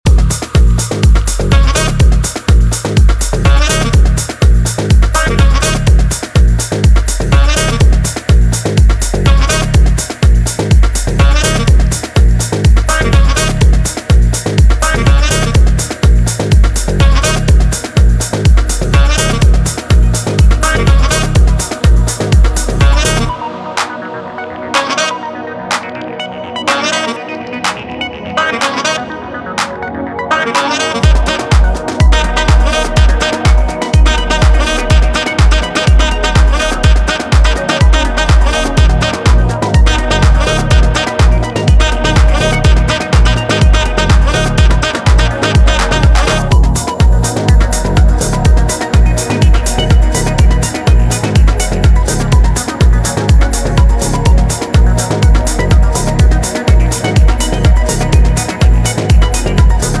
ディープ・ハウスのアトモスフェリックな幻影を追う
長いディスコ/ハウス史への眼差しを感じさせる楽曲を展開